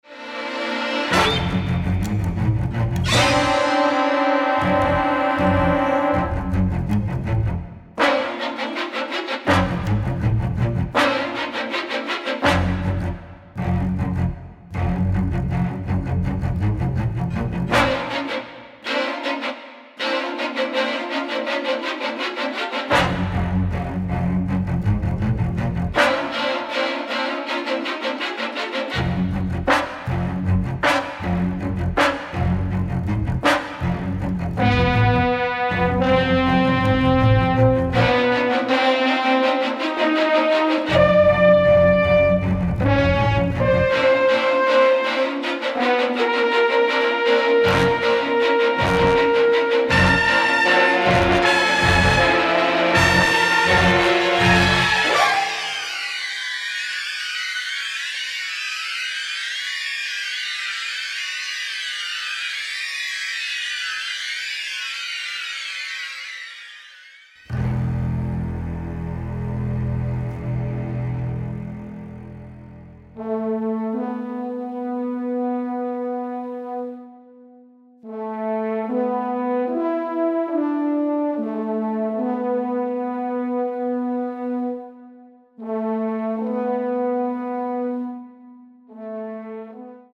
original motion picture score